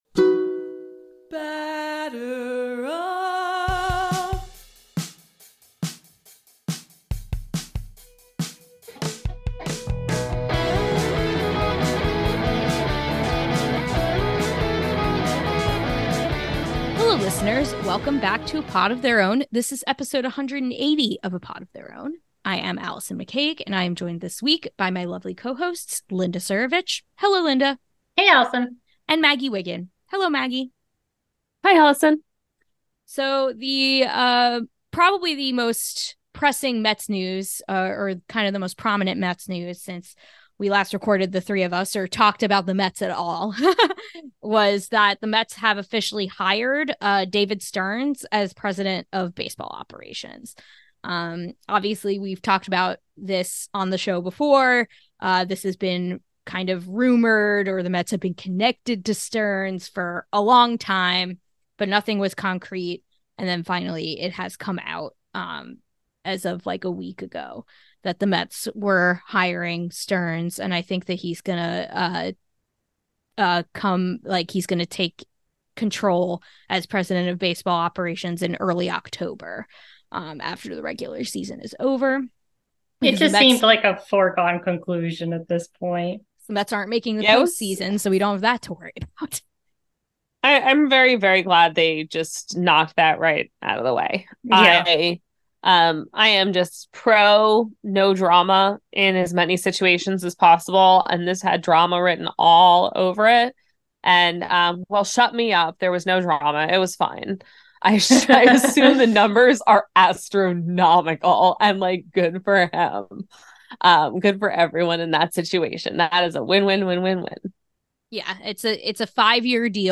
Welcome back to A Pod of Their Own, an all-women led Home Run Applesauce podcast where we talk all things Mets, social justice issues in baseball, and normalize female voices in the sports podcasting space.
This week, we begin by discussing the Mets’ hiring of David Stearns and what this may mean for the future of the organization. We also make some sad trumpet noises and lament that we will not see Edwin Díaz this year. Next, we talk about the Washington Nationals and Los Angeles Angels of Anaheim both making utter fools out of themselves.